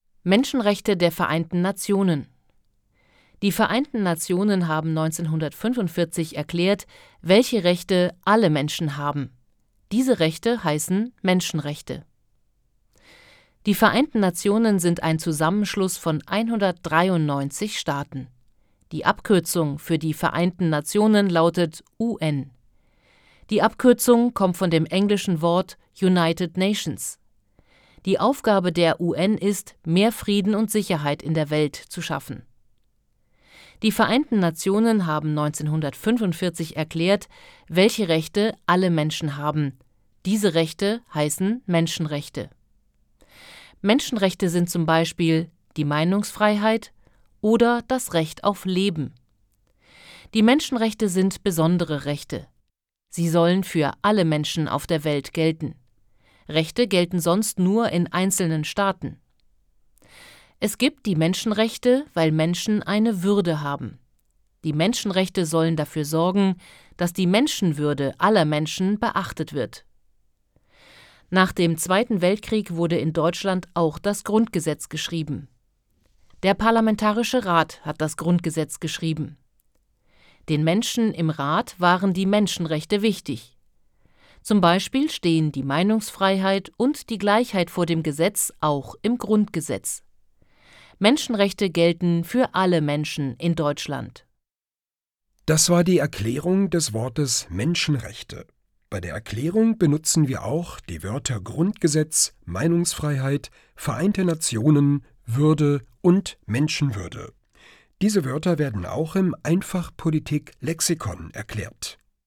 In einfacher Sprache